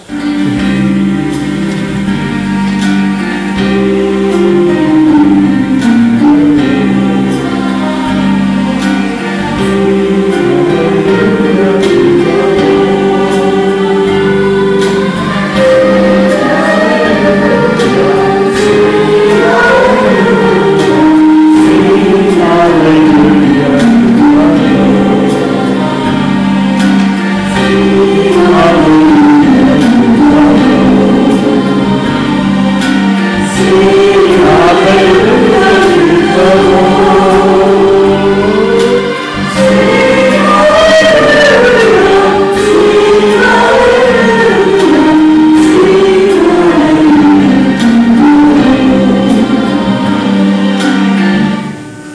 Bethel Church Service
Prelude: "Sing Alleluia to the Lord
Opening Prayer